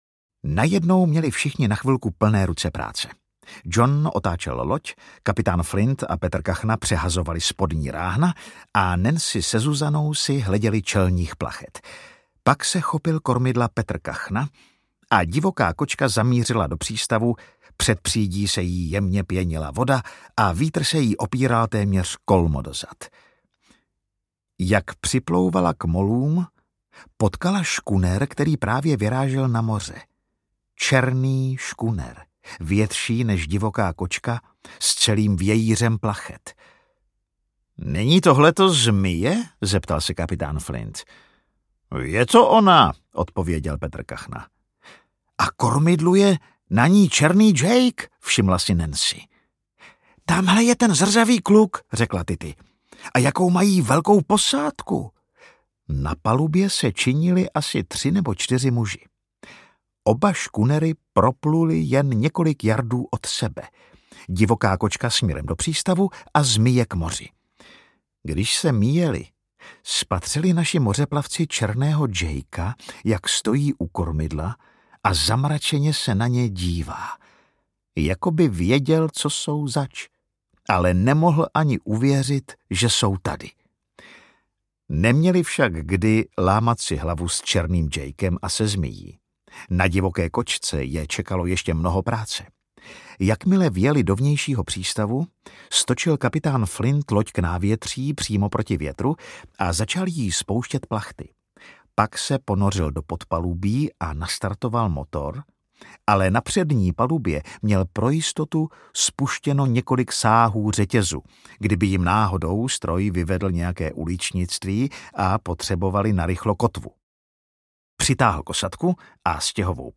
Petr Kachna audiokniha
Ukázka z knihy
Vyrobilo studio Soundguru.